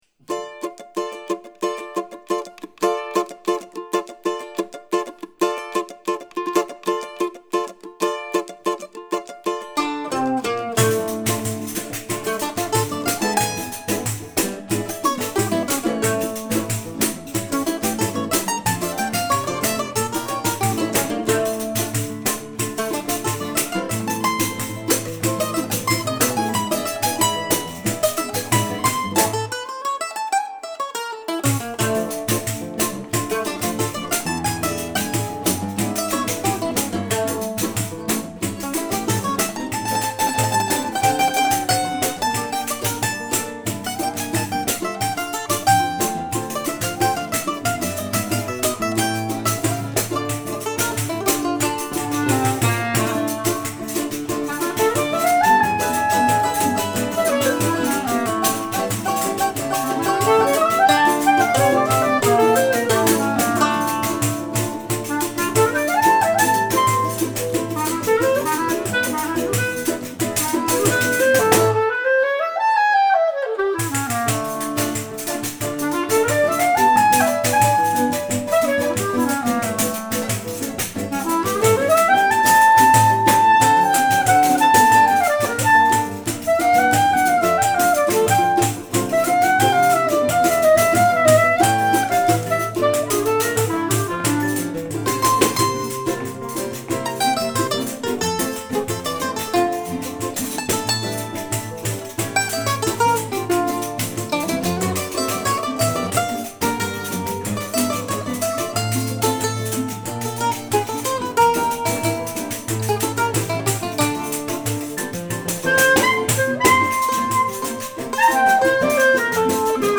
après une séance de travail Choro